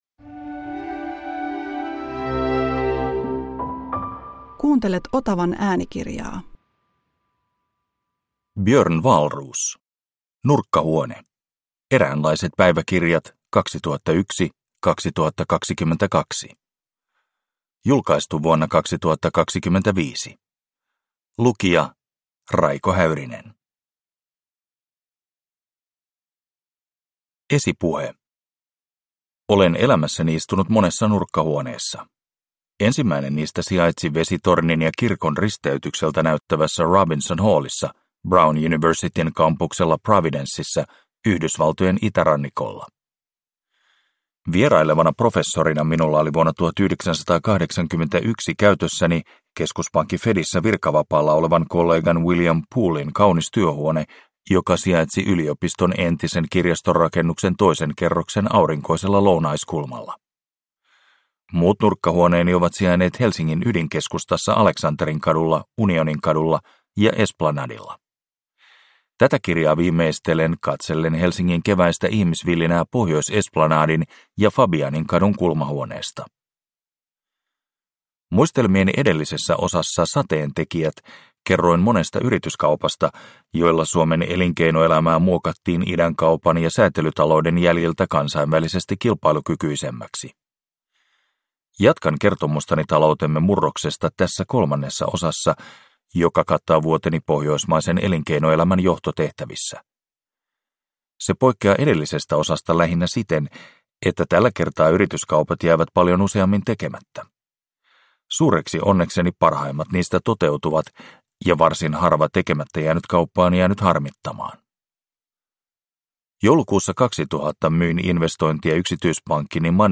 Nurkkahuone – Ljudbok